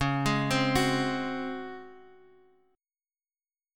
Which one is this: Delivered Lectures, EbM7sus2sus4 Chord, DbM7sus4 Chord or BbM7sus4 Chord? DbM7sus4 Chord